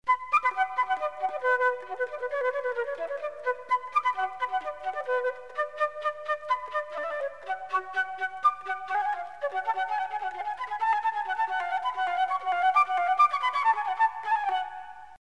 Шутка(Badenerie)И-СБах=сольноеИсполнение(фрагм)